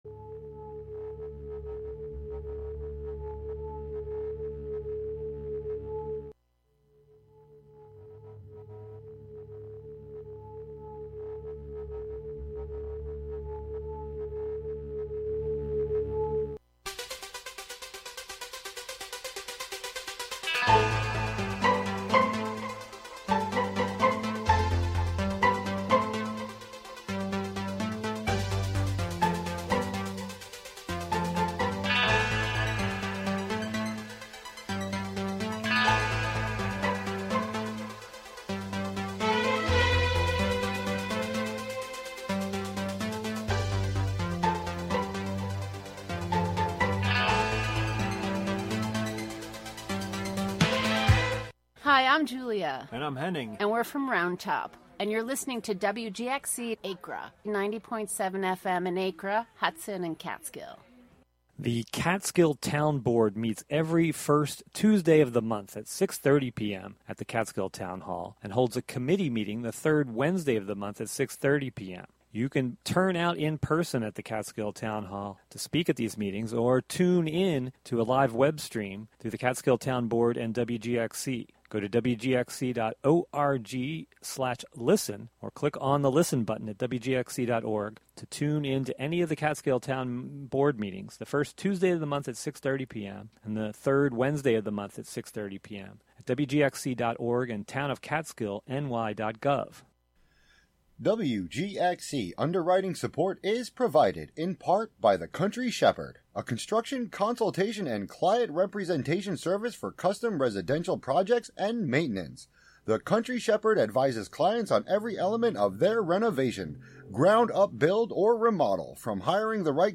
Contributions from many WGXC programmers.
The show is a place for a community conversation about issues, with music, and more.